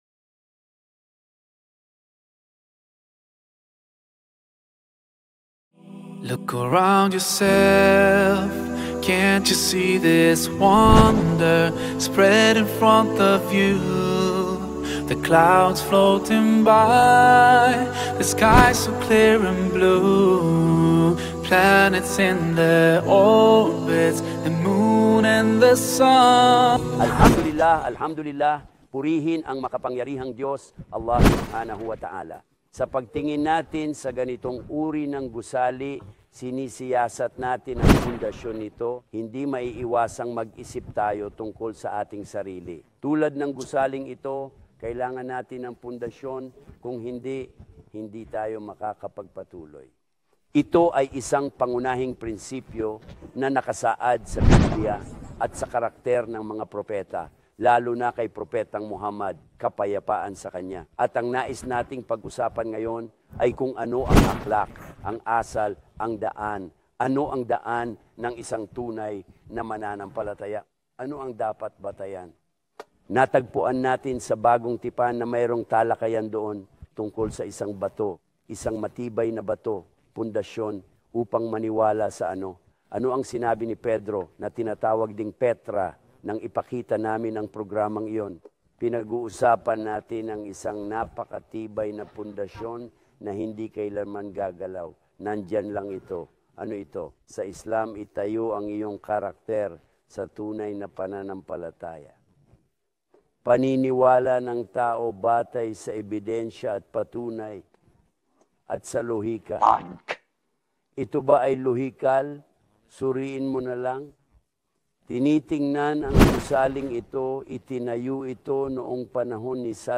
filmed in the backdrop of scenic landscapes and historic places of Jordan. In this episode, he explains how Islam builds character. He also talks about the rights of God and others.